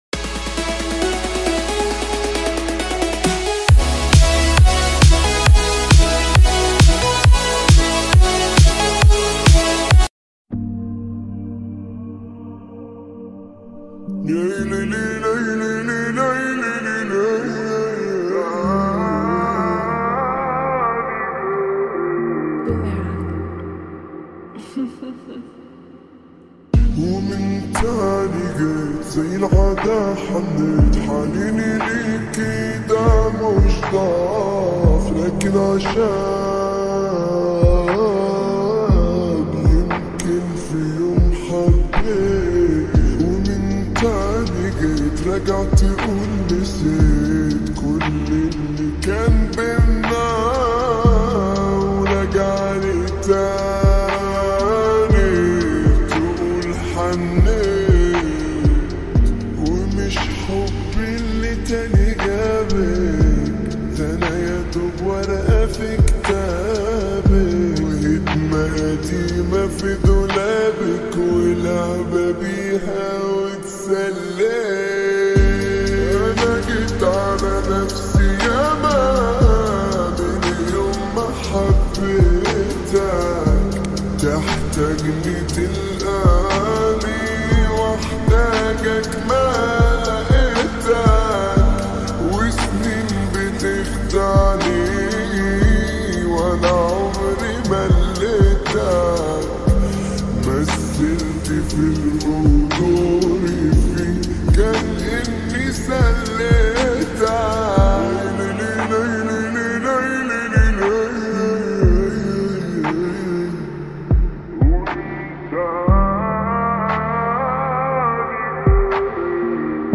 (Slowed & Reverb)